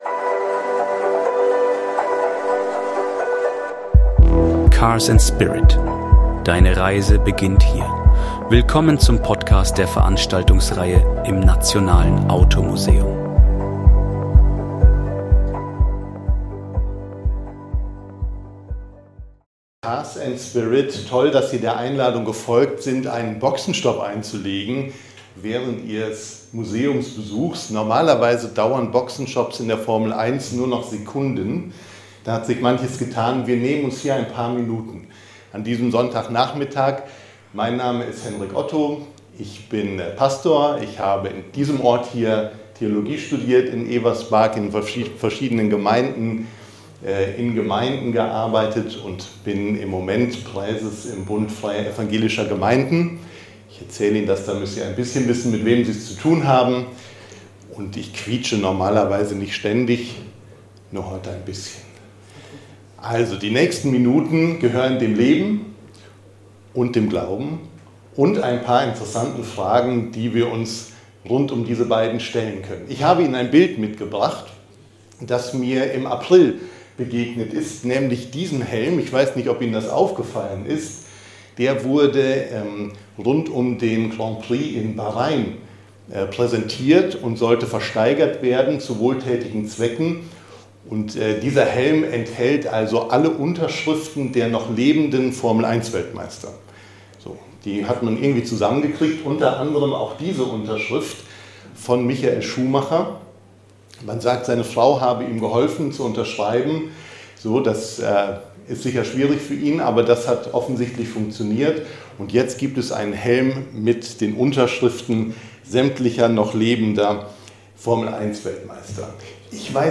Ein bewegender Impuls mit Tiefgang, Motorsportleidenschaft und persönlichen Geschichten. Lassen Sie sich überraschen, was ein Schrumpelapfel mit Ihrer Bestimmung zu tun hat.